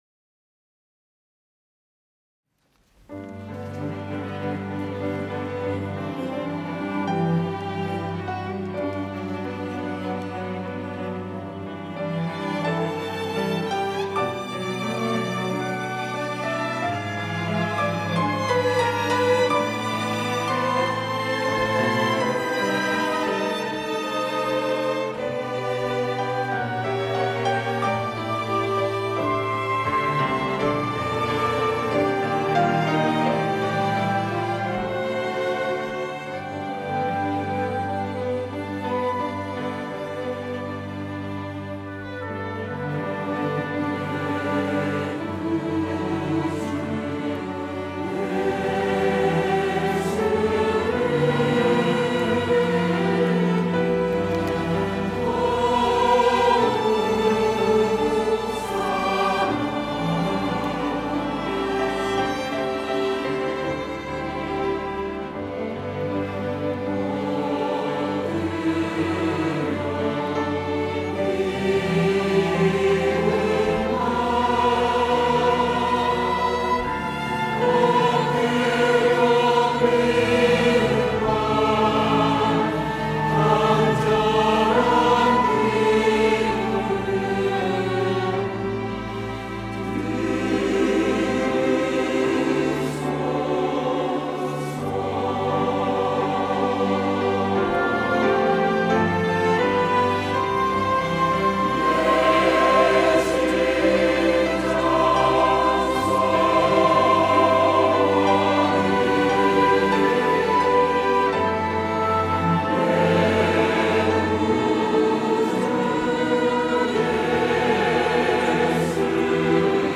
호산나(주일3부) - 내 구주 예수를 더욱 사랑
찬양대